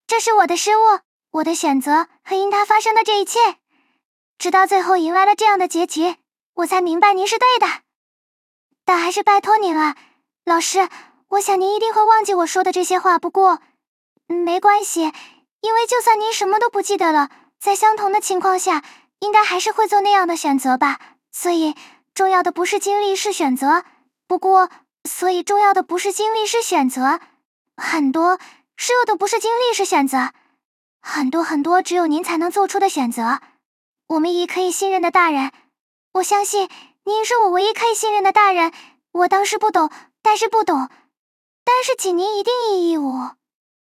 GPT-SoVITS - 1 min voice data can also be used to train a good TTS model! (few shot voice cloning)